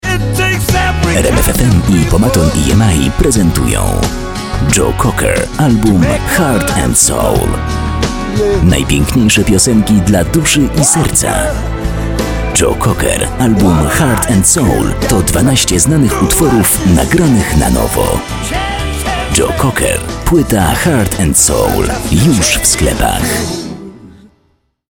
polnischer Sprecher
Kein Dialekt
voice over artist polish